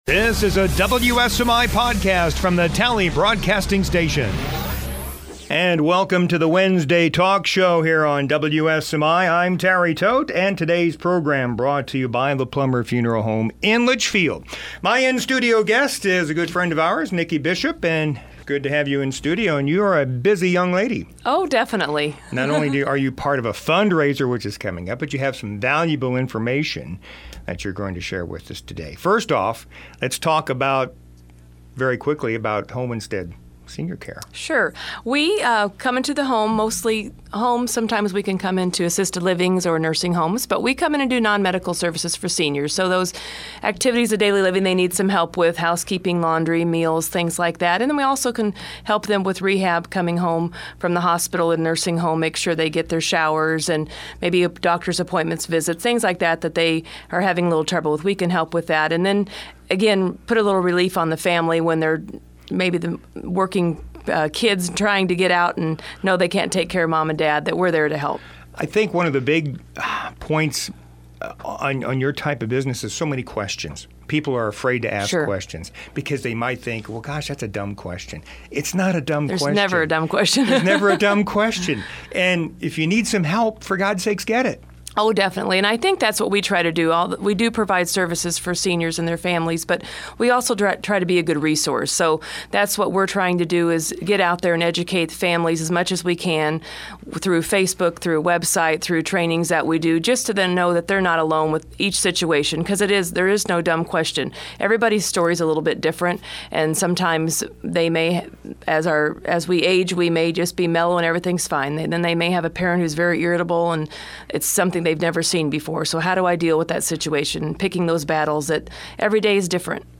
08/19/2015 Wednesday Talk Show Guest